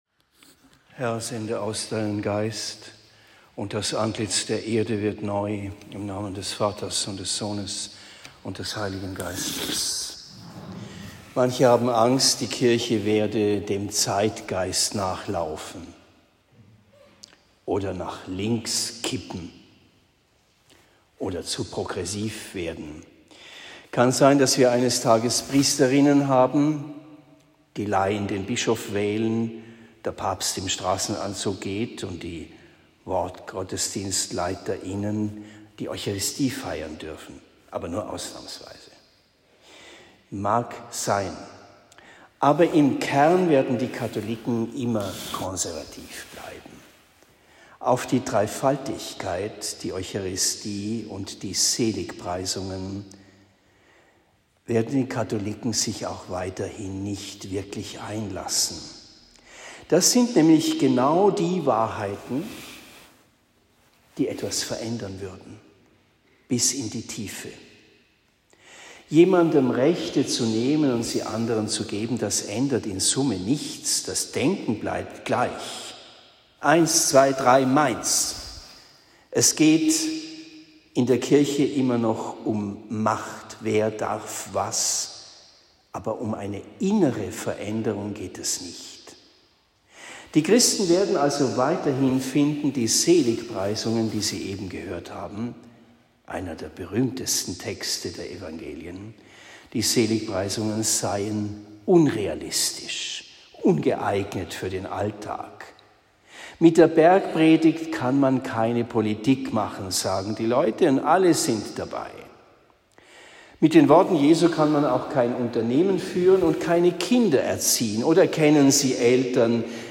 Predigt am 12. Juni 2023 in Bischbrunn